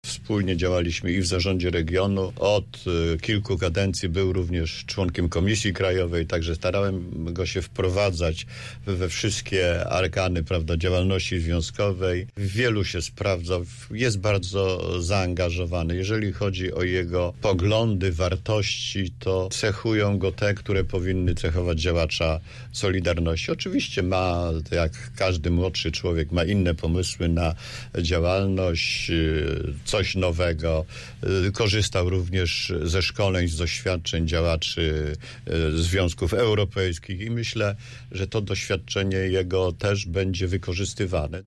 który był gościem porannej rozmowy Radia Zielona Góra.